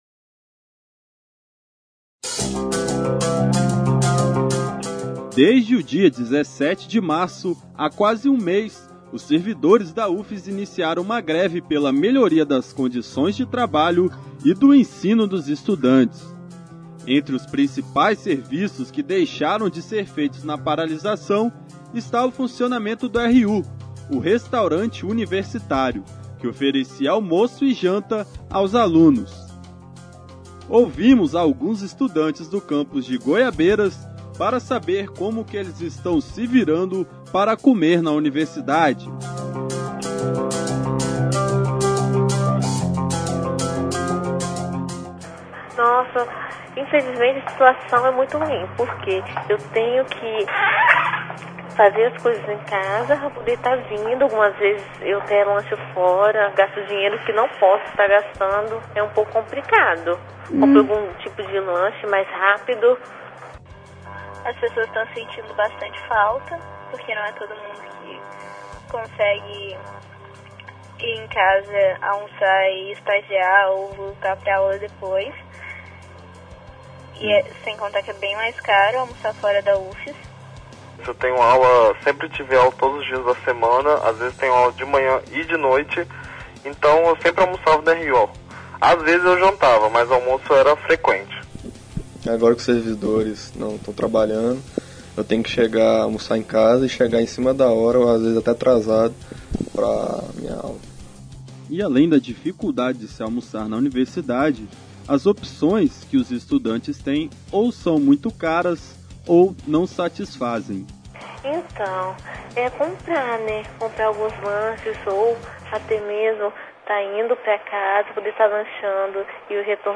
Conversamos com alunos do campus de Goiabeiras para saber como eles estão contornando a situação.